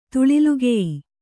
♪ tuḷiugeyi